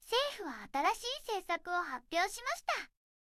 referenceの音声と書き起こしを入力して、Targetのテキストを生成してみました。結構いい感じですが、起伏がオーバーになることが多いのが少し気になりました。
色々パラメータ変えて出力を見てみました。全部同じじゃないですか！